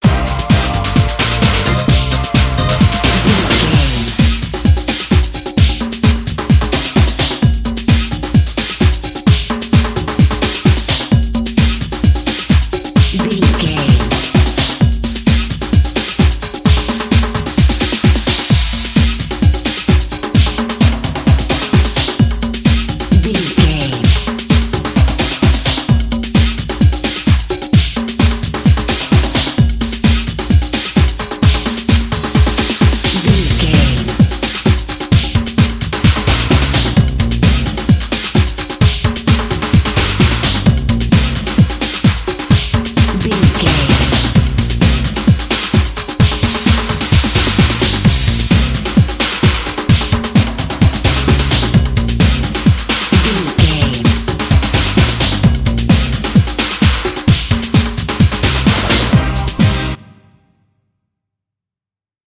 Aeolian/Minor
Fast
drum machine
synthesiser
electric piano
bass guitar
conga
Eurodance